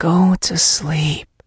sandy_kill_vo_05.ogg